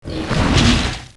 Heroes3_-_Azure_Dragon_-_AttackSound.ogg